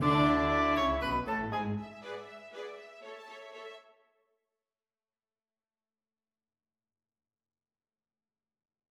Она состоит из двух контрастных фраз: «сначала решительной, затем мягкой и заговорщической»[5].